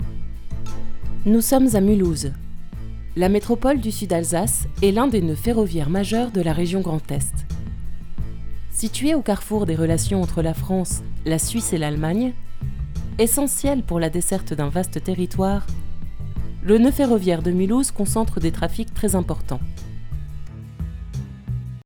Enregistrements qualité studio
Voix institutionelle
Claire et impactante, pour vos communications institutionnelles.
voix-insitutionnelle.wav